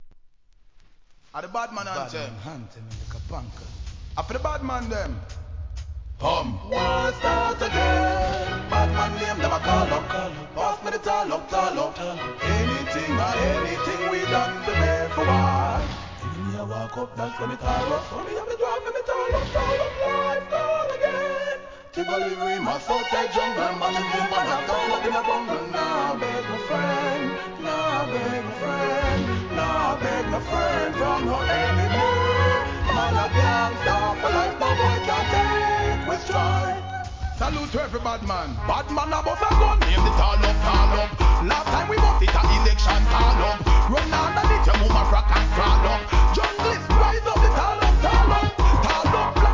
REGGAE
2002年、得意のオペラ調イントロからイケイケのDANCE HALLで流行!!!